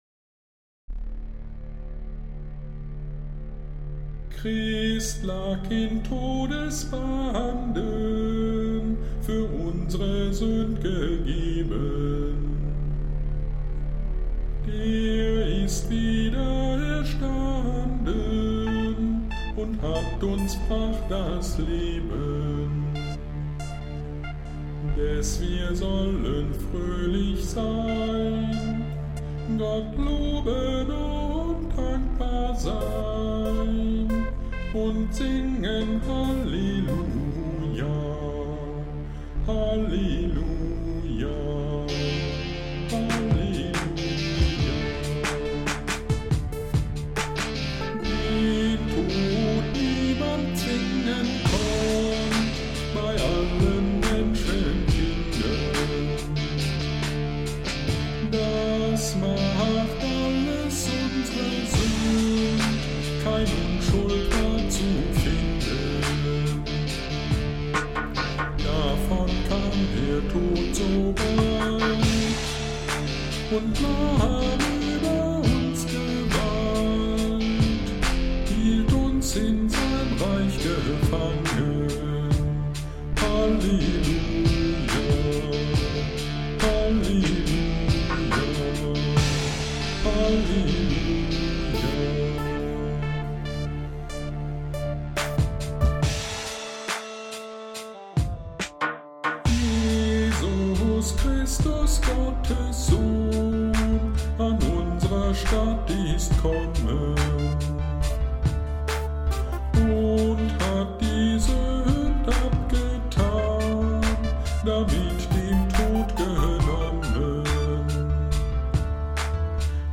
FaGo Ostern 2023 – Predigt zu Matthäus 28.1-10